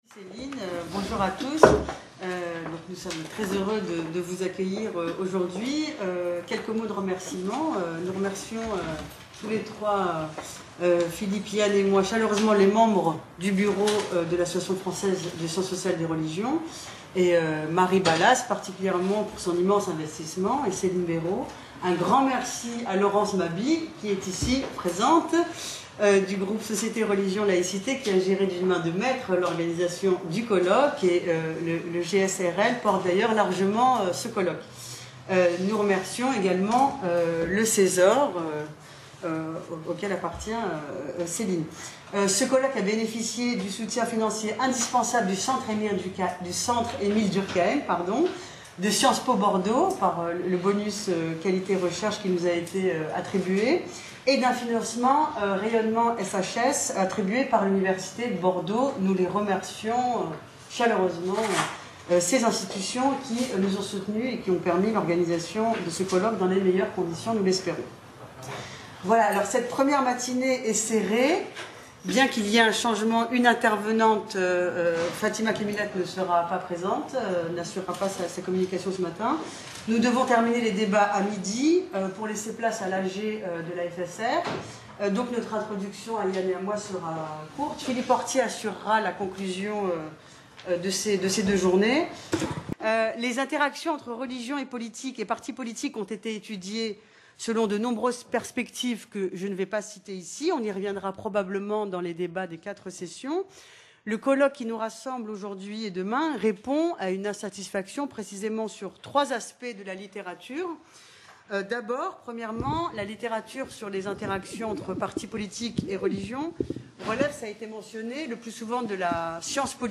Cours/Séminaire 00:17:44 Favoris L’utilisation de la ressource religieuse par un parti « laïque » dans la Tunisie de l’après-révolut…